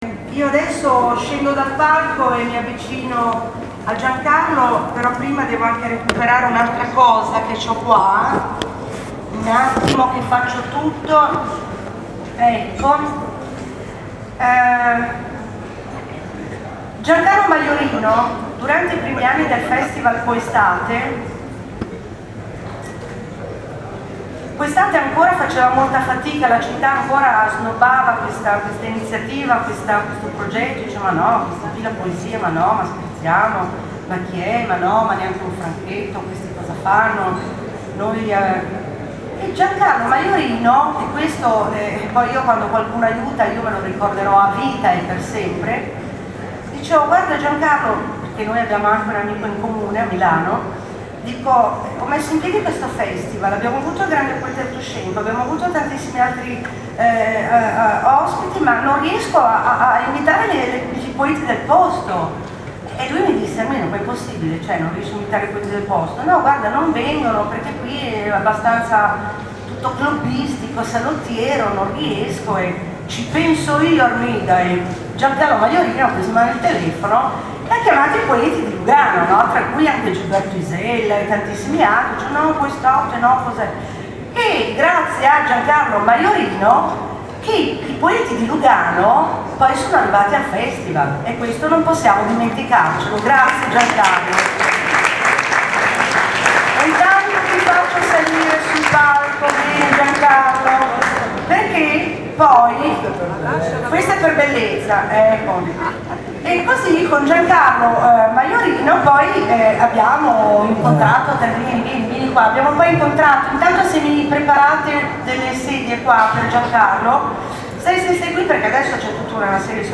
F ESTIVAL P OESTATE 2012 L UGANO � 16 a edizione Lugano, Piazza Riforma � Patio Municipio 31 maggio � 2 giugno 2012 http
Lugano 2 giugno 2012.MP3